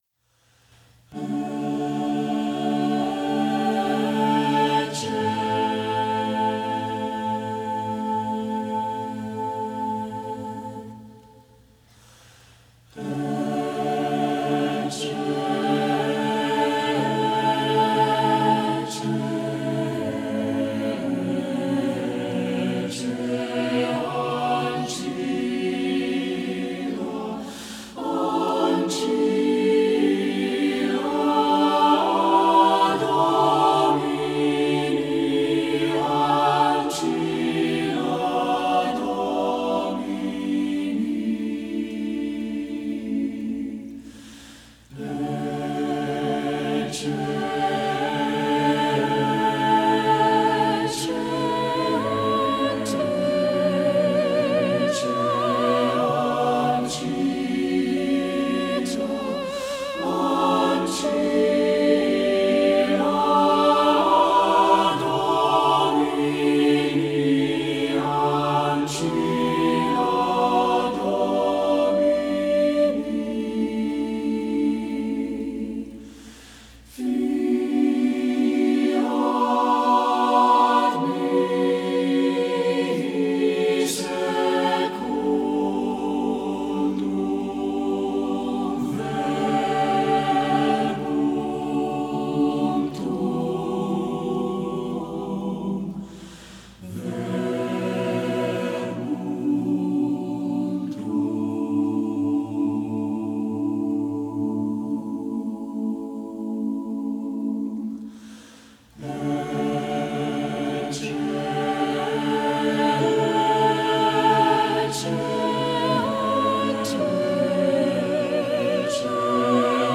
SATB, unaccompanied
Voicing: SATB